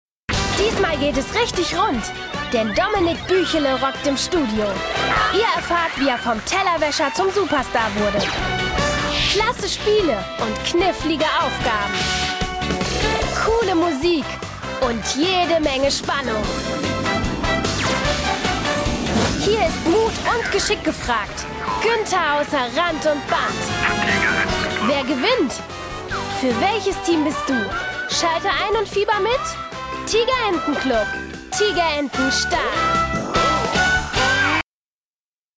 tv-trailer-demo.mp3